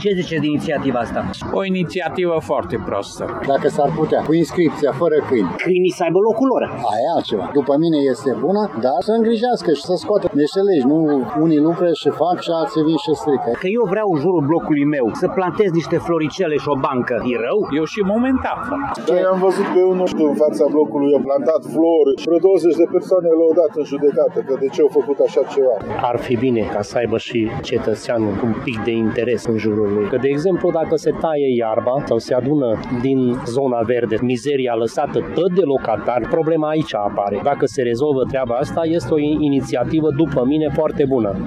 Tg.mureșenii atrag atenția că spațiile verzi trebuie protejate de vandalizare și de accesul animalelor de companie: